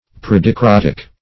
Predicrotic \Pre`di*crot"ic\, a. (Physiol.)